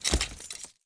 PickupWeapon.mp3